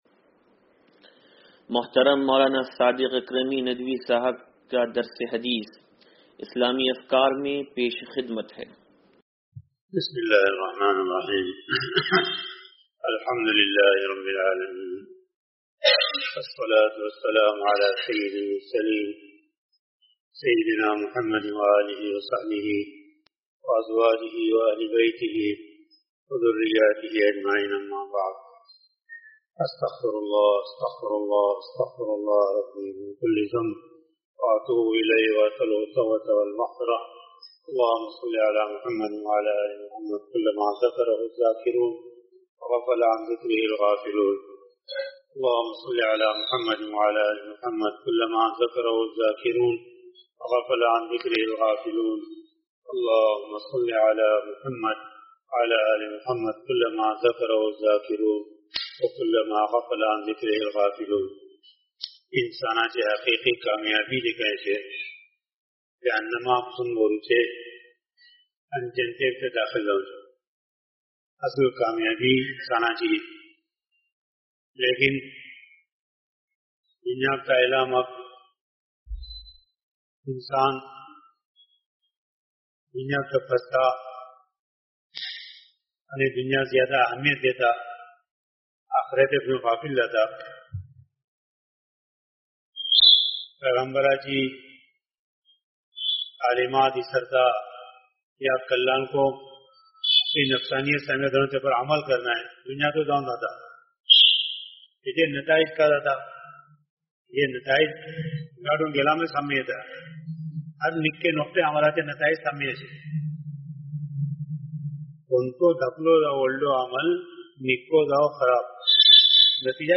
درس حدیث نمبر 0572